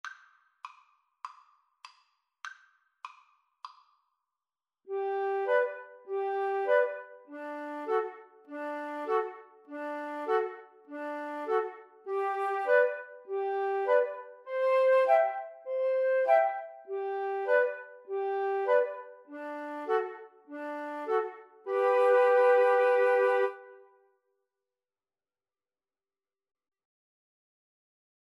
Free Sheet music for Flute Trio
G major (Sounding Pitch) (View more G major Music for Flute Trio )
With a swing!